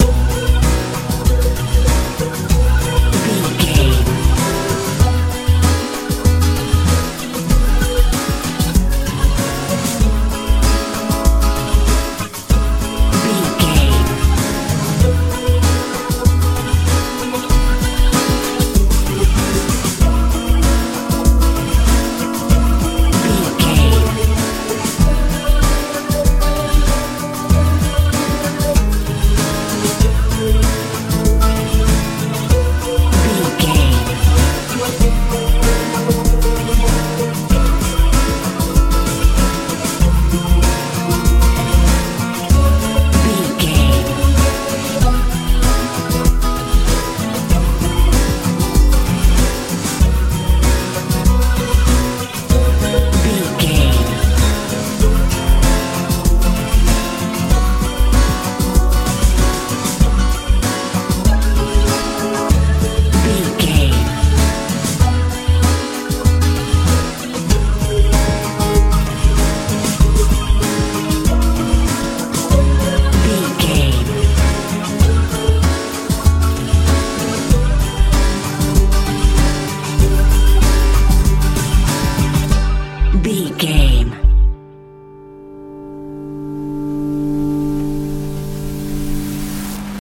modern pop feel
Aeolian/Minor
hopeful
joyful
acoustic guitar
synthesiser
bass guitar
drums
80s
strange
soft
soothing